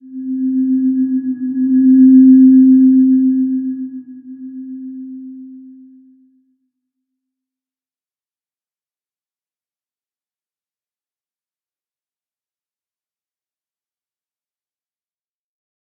Slow-Distant-Chime-C4-p.wav